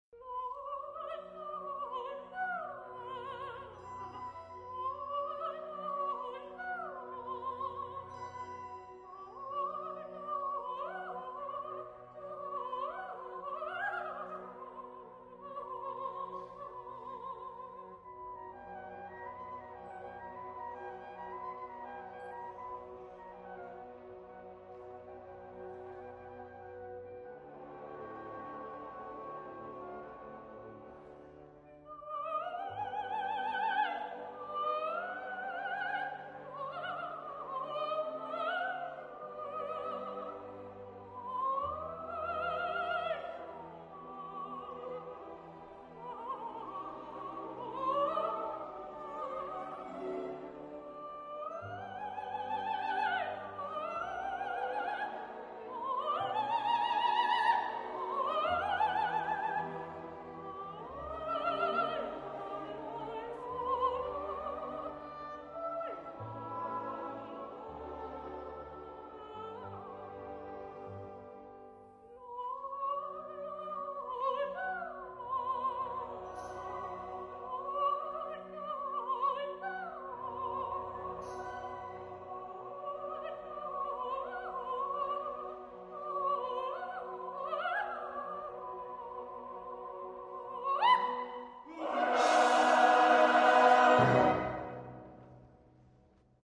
danza & coro 1:36 Min
Fleana [Sopran]